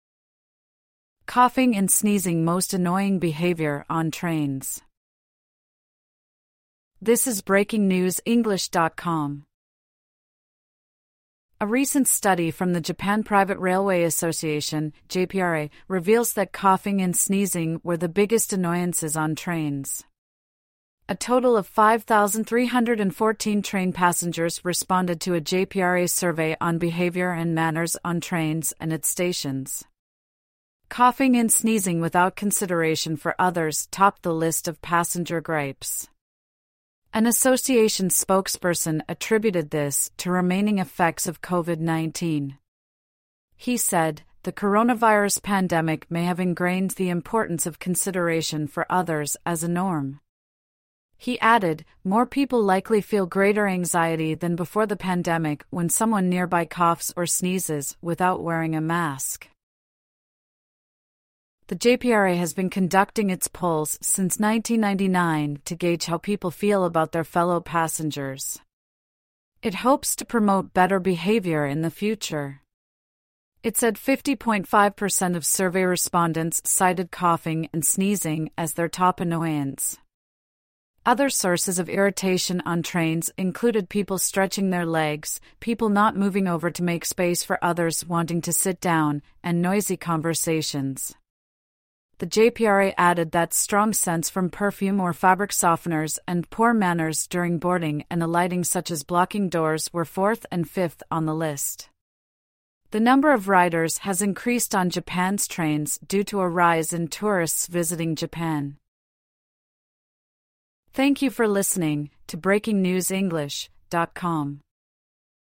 AUDIO(Normal)